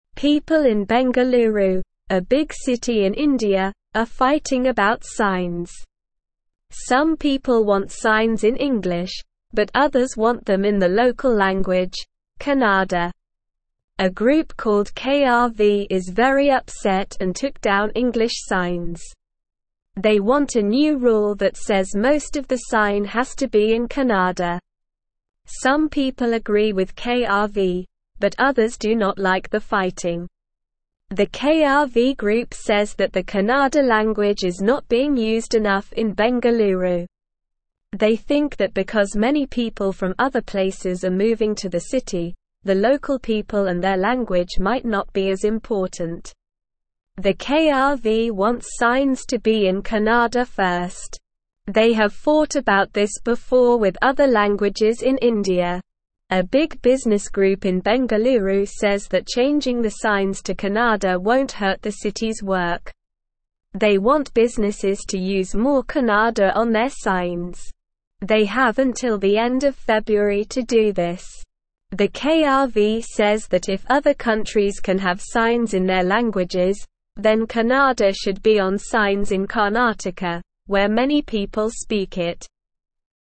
Slow
English-Newsroom-Lower-Intermediate-SLOW-Reading-People-in-India-want-signs-in-their-language.mp3